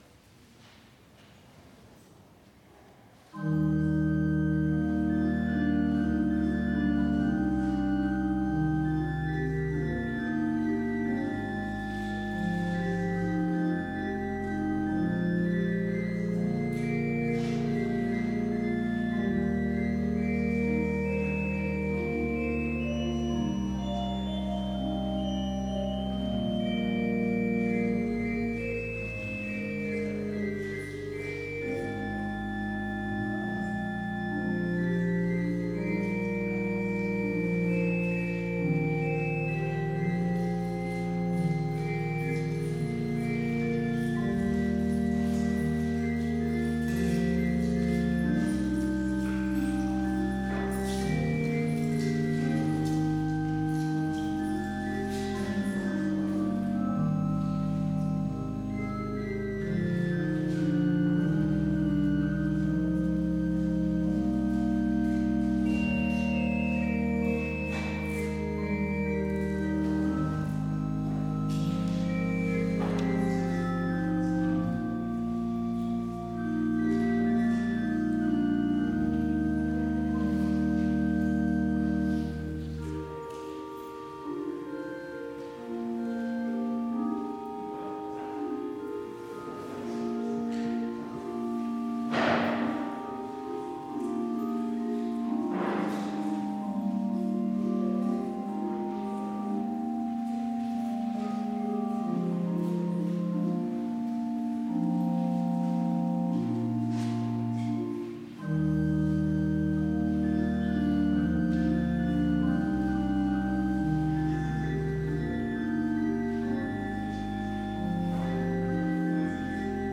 Complete service audio for Chapel - November 11, 2020